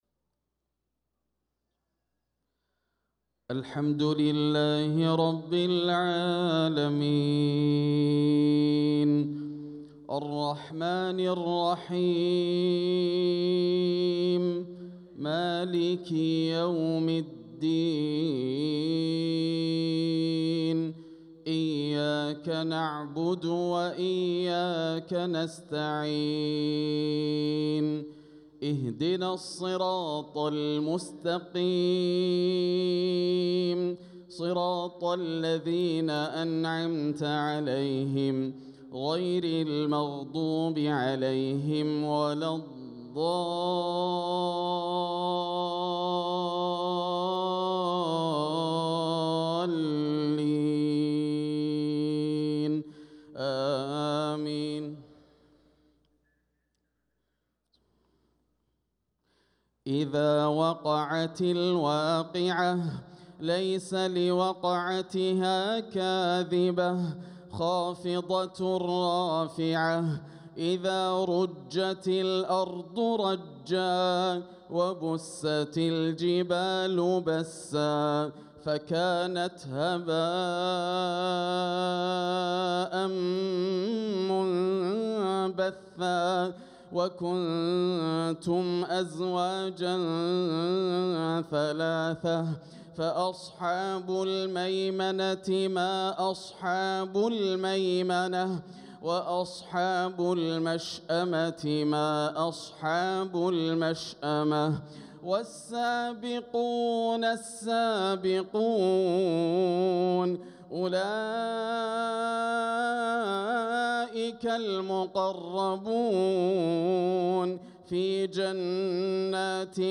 صلاة العشاء للقارئ ياسر الدوسري 3 ربيع الأول 1446 هـ
تِلَاوَات الْحَرَمَيْن .